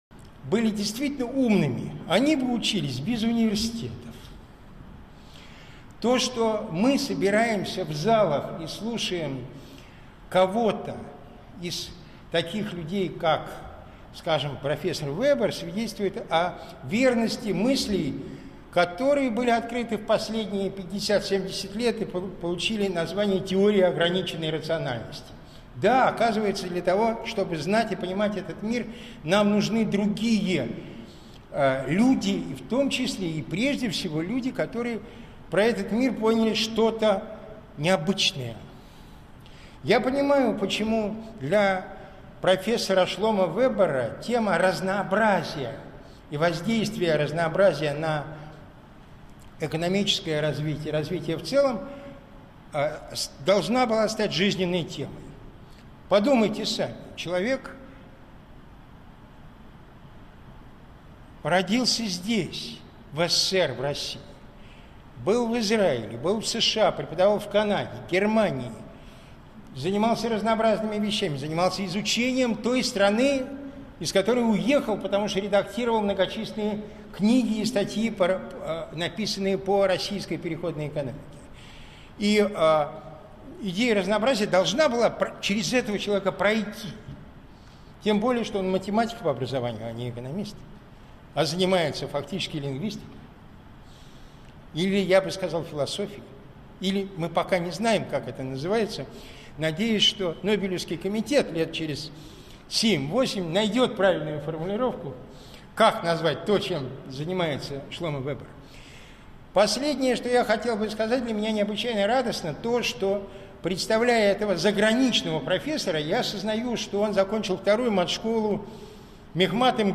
Аудиокнига Социальное многообразие России - шанс или вызов?
Прослушать и бесплатно скачать фрагмент аудиокниги